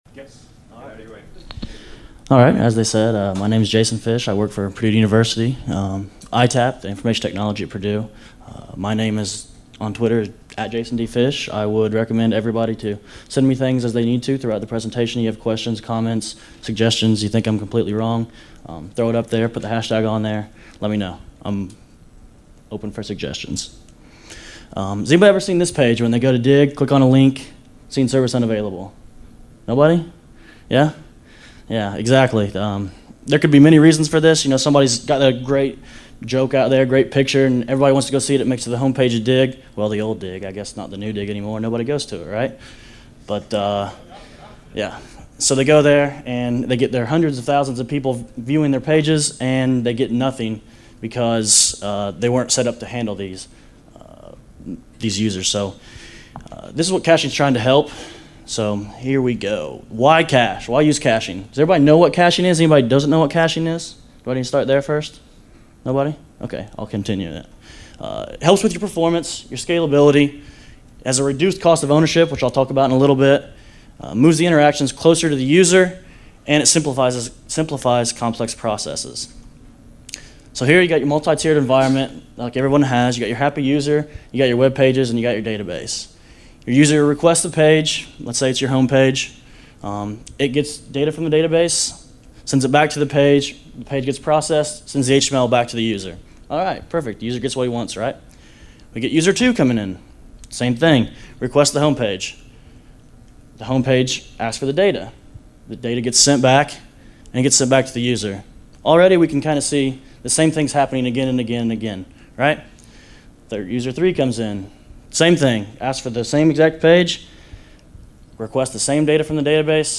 This presentation will describe what caching is and how it can improve your site’s performance and sustained growth.